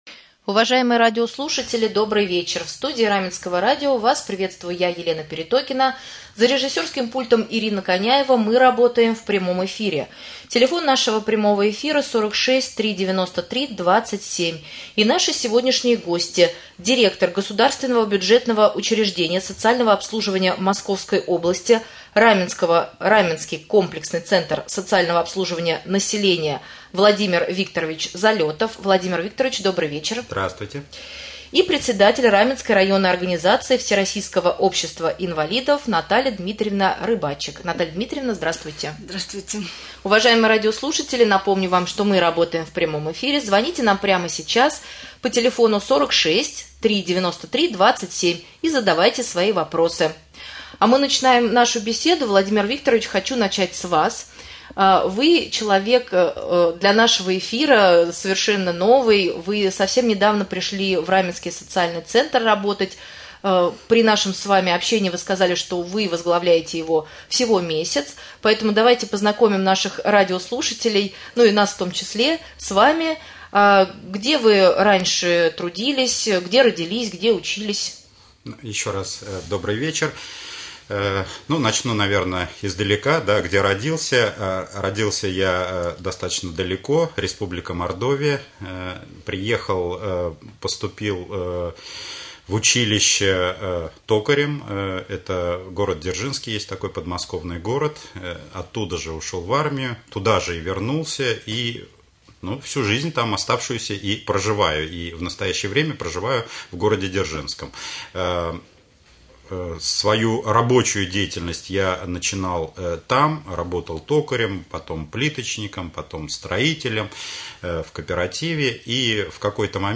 Прямой эфир.